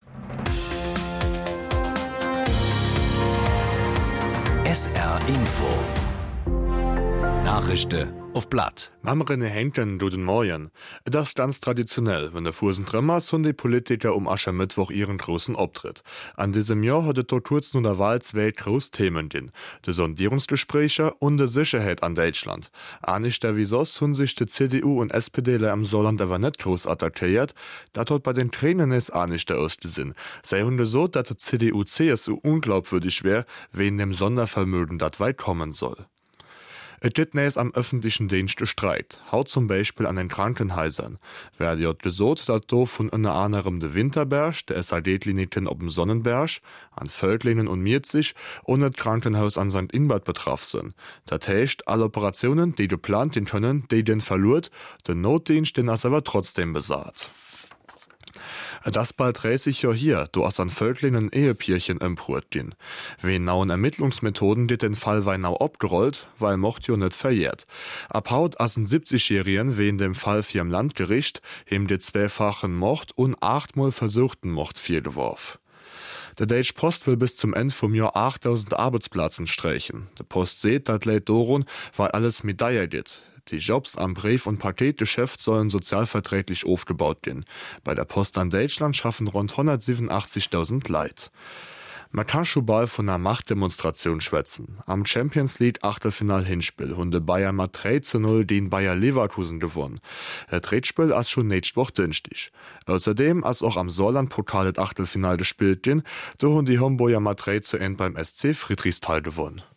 Von Montag bis Freitag gibt es bei SR 3 Saarlandwelle täglich um 08:30 Uhr Schlagzeilen in Mundart. Die "Nachrischde uff platt" werden mal in moselfränkischer, mal in rheinfränkischer Mundart präsentiert. Von Rappweiler bis Dudweiler, von Dillingen bis Püttlingen setzt sich das Team aus Sprechern ganz verschiedener Mundartfärbungen zusammen